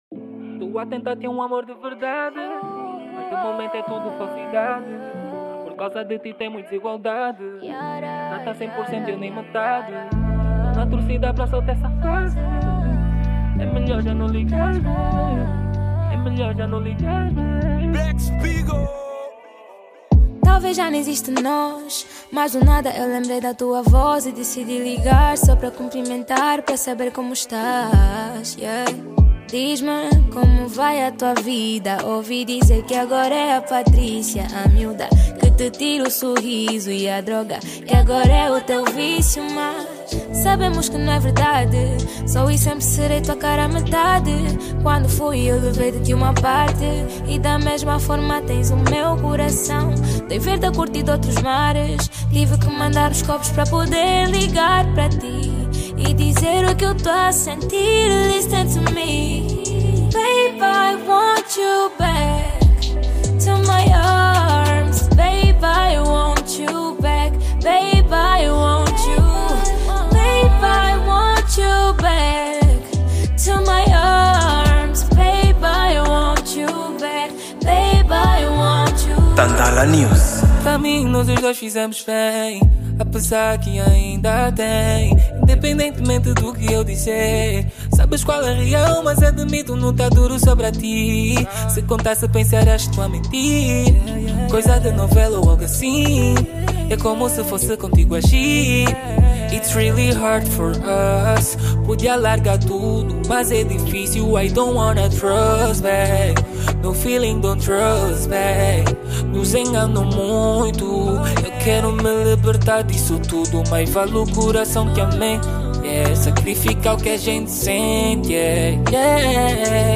Gênero: Zouk